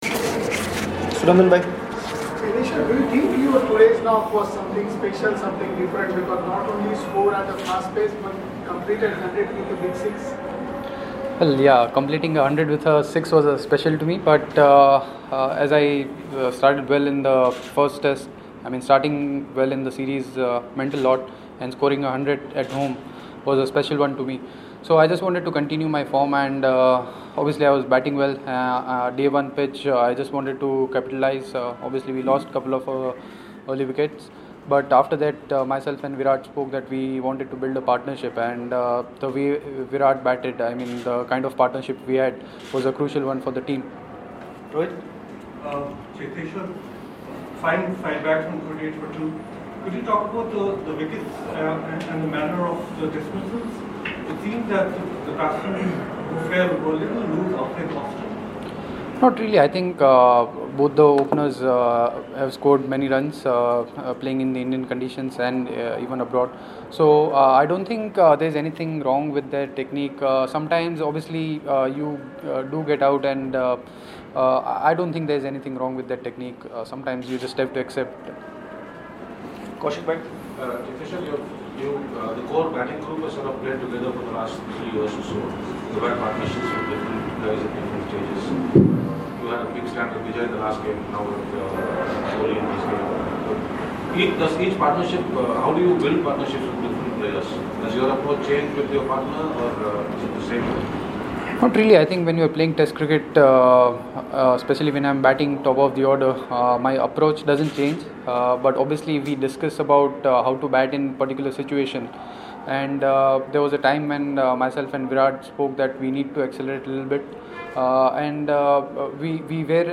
LISTEN: Cheteshwar Pujara Speaking After Vizag Heroics